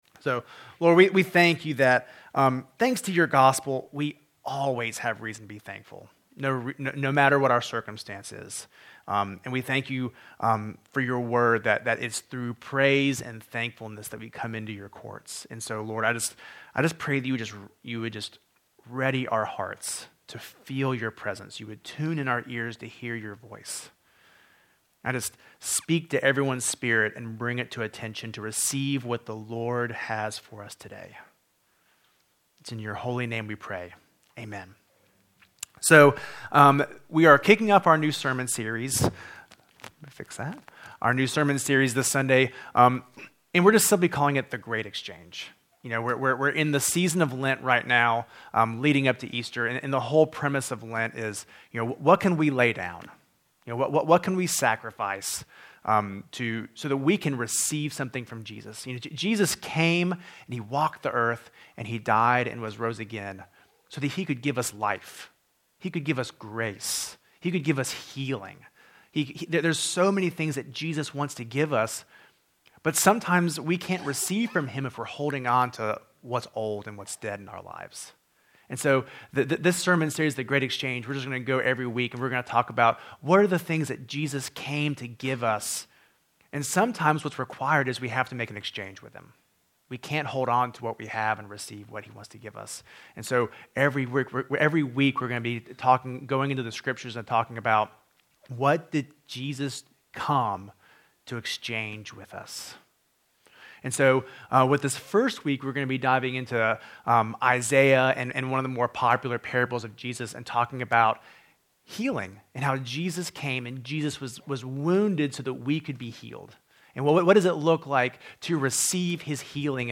Project:Re3 RE3 Sermon Audio
The-Great-Exchange-Sermon-Series-Wk01.MP3